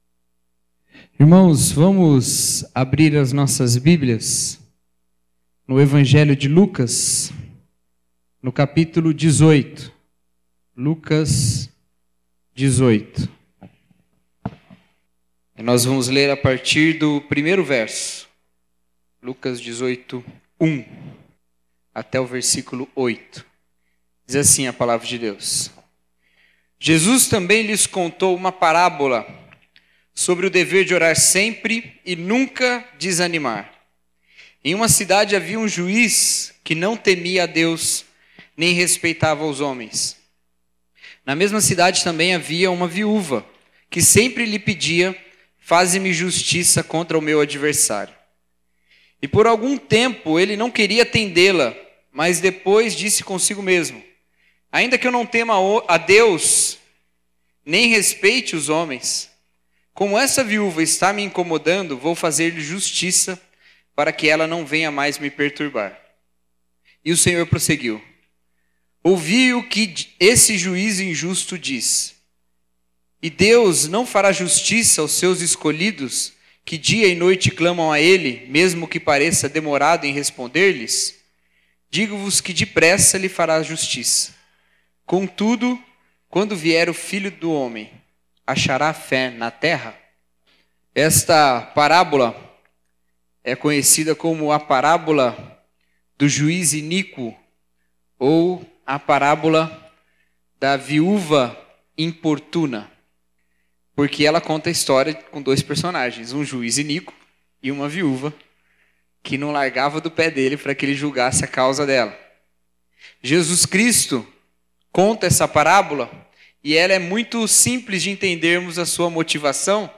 Mensagem: Orar Sempre e não Desanimar